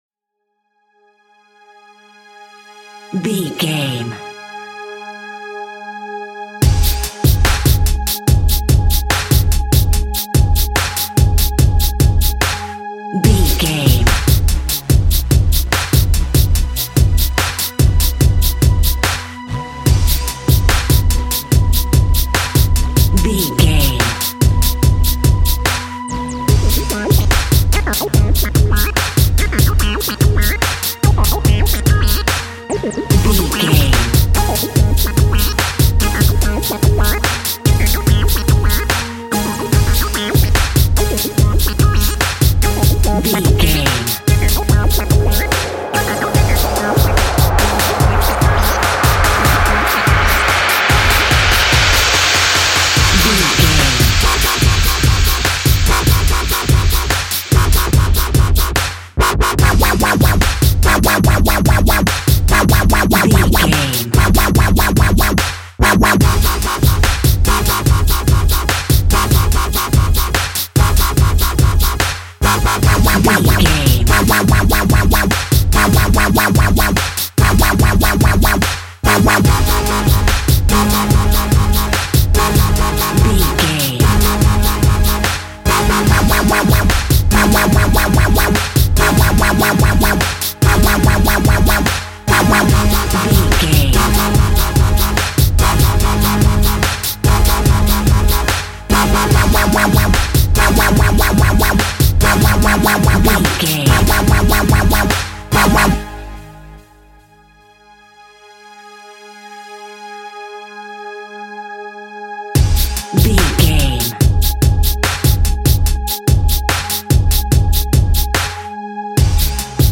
Dub Music.
Epic / Action
Fast paced
Aeolian/Minor
G#
aggressive
powerful
dark
driving
energetic
intense
disturbing
strings
synthesiser
drums
drum machine
breakbeat
synth leads
synth bass